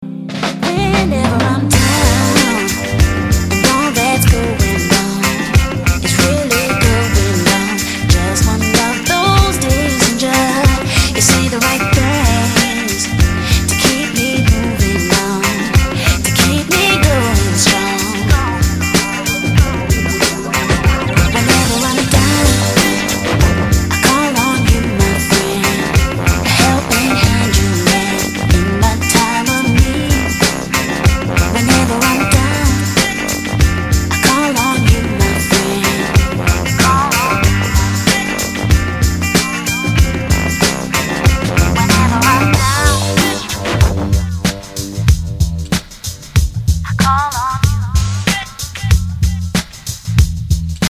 Категория: Спокойные